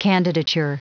Prononciation du mot candidature en anglais (fichier audio)
Prononciation du mot : candidature